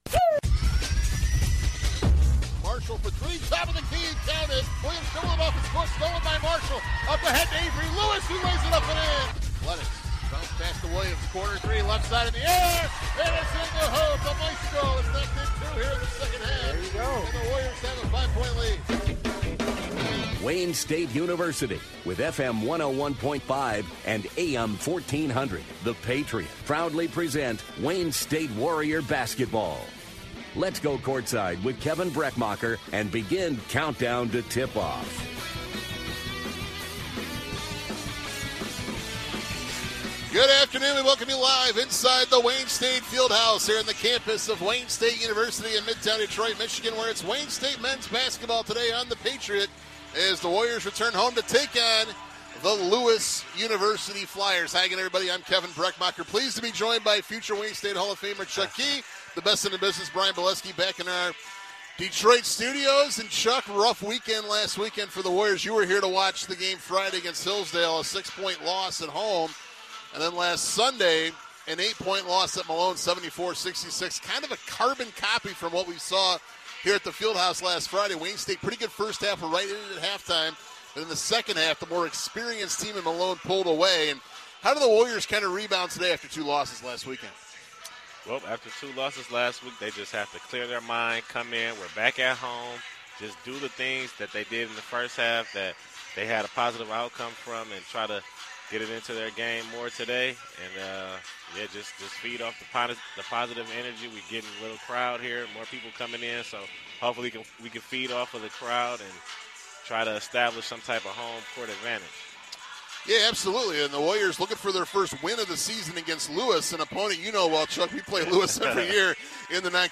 WDTK Broadcast of Men's Basketball vs. Lewis - Nov. 18, 2023